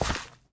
sounds / step / gravel3.ogg
gravel3.ogg